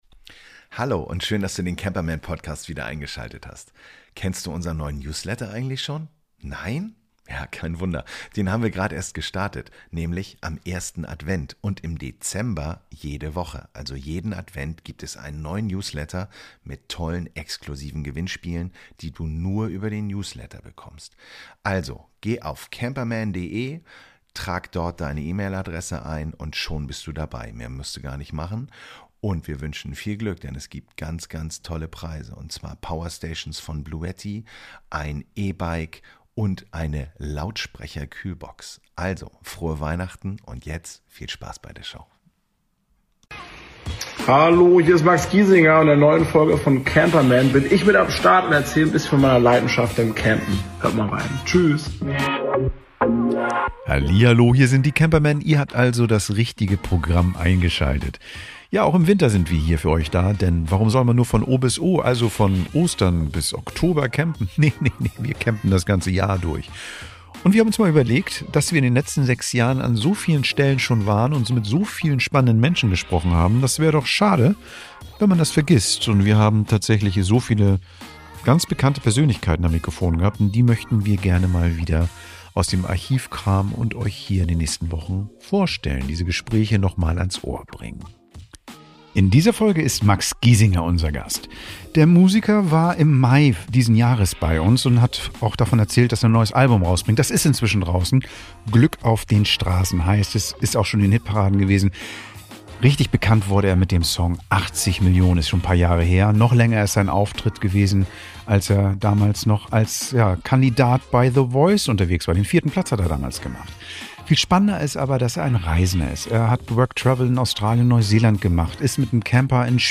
Zum Start der Reihe "VIP-Extra" veröffentlichen wir unser Interview mit dem Sänger Max Giesinger.